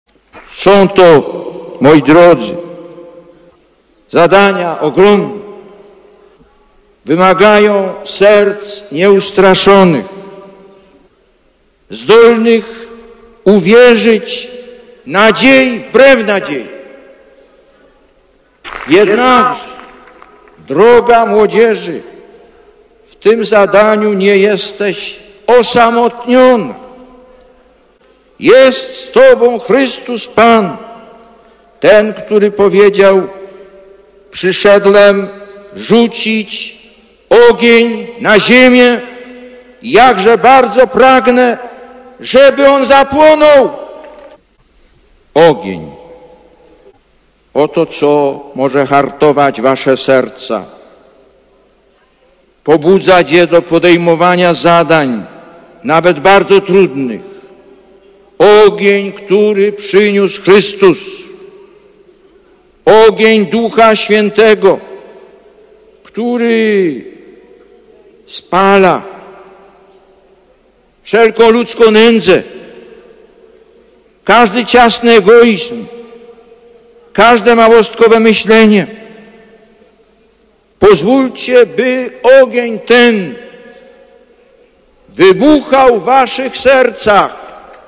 Lektor: Z homilii w czasie Mszy św. (tamże -